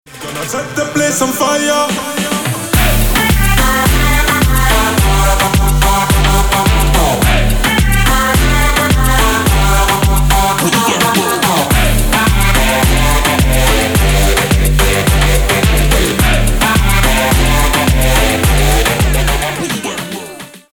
• Качество: 320, Stereo
dancehall
dance
Electronic
EDM
club